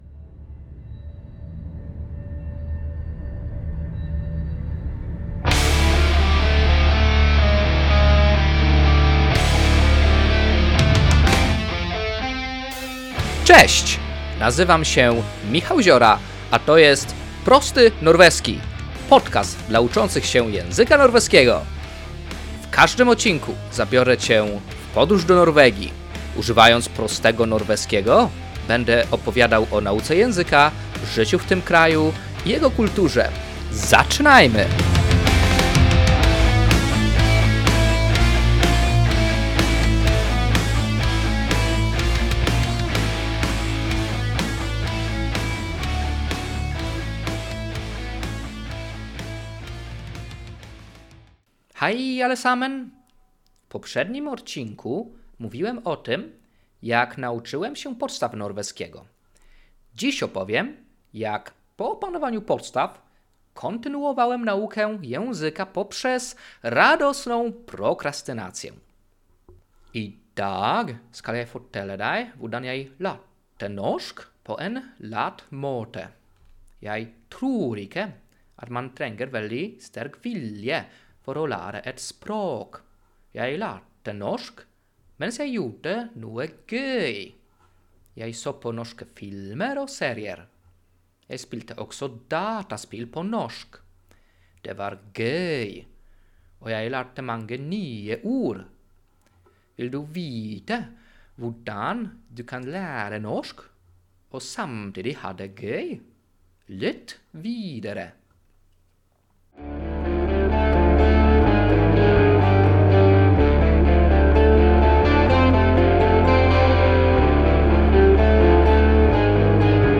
Muzyka w intro: